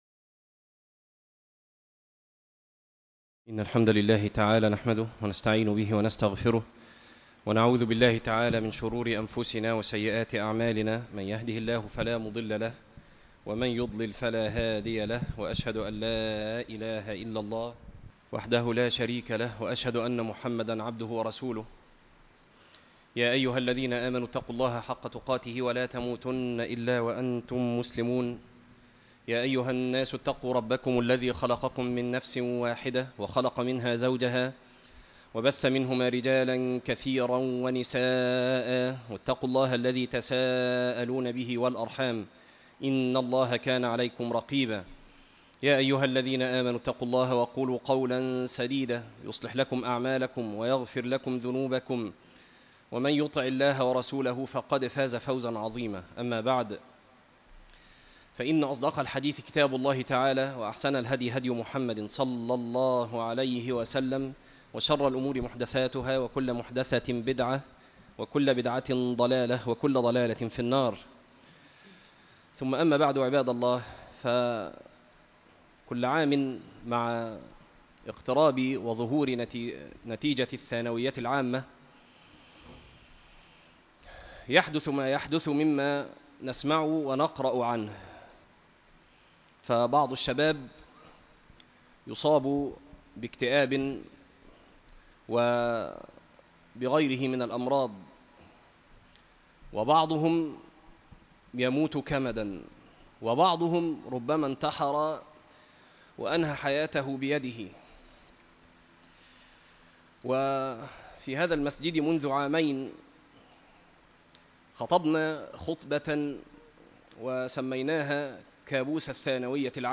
عنوان المادة صيحة نذير قبل نتيجة الثانوية - خطبة تاريخ التحميل الأثنين 13 ابريل 2026 مـ حجم المادة 14.90 ميجا بايت عدد الزيارات 3 زيارة عدد مرات الحفظ 1 مرة إستماع المادة حفظ المادة اضف تعليقك أرسل لصديق